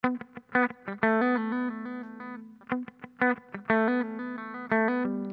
Sons et loops gratuits de guitares rythmiques 100bpm
Guitare rythmique 69